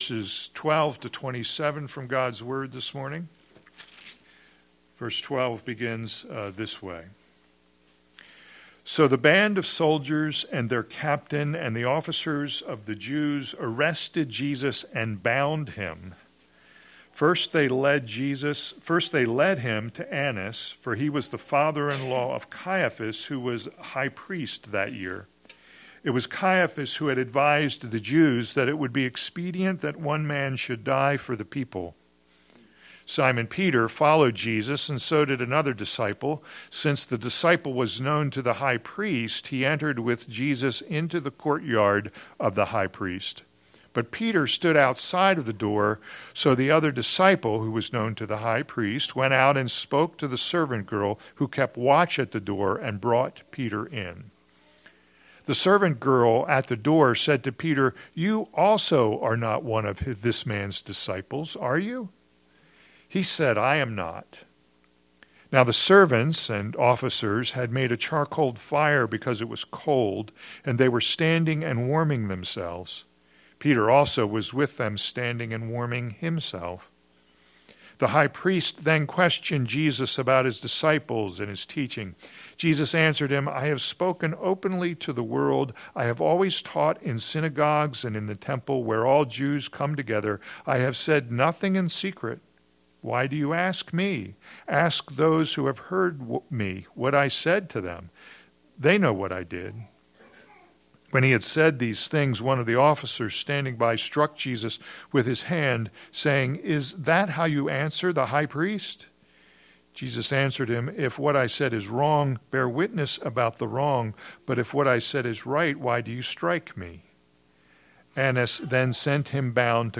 All Sermons The Trial of Jesus and Peter